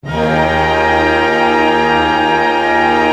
Index of /90_sSampleCDs/Roland LCDP08 Symphony Orchestra/ORC_ChordCluster/ORC_Pentatonic